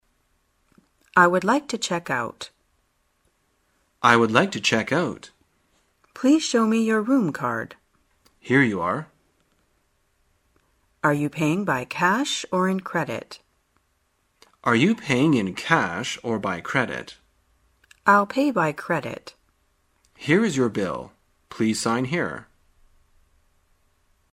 在线英语听力室生活口语天天说 第148期:怎样结账退房的听力文件下载,《生活口语天天说》栏目将日常生活中最常用到的口语句型进行收集和重点讲解。真人发音配字幕帮助英语爱好者们练习听力并进行口语跟读。